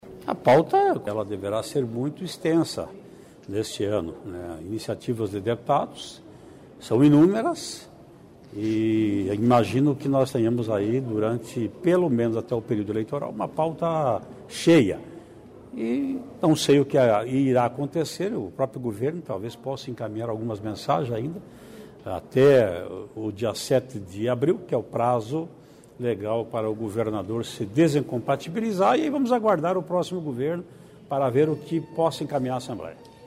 Ouça trecho da entrevista com presidente a Assembleia Legislativa no começo da tarde desta segunda-feira (26), onde ele afirma que deputados têm apresentado diversos projetos de lei, além,  é calro, das pautas que devem vir do Governo.